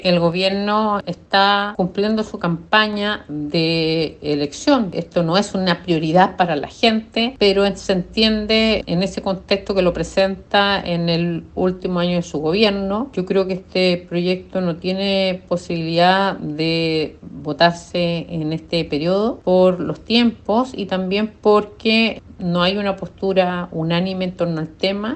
Por su parte, la diputada y jefa del comité de Demócratas, Amarillos e independientes, Joanna Pérez, aseguró que no dan los tiempos para debatir la iniciativa en el corto plazo.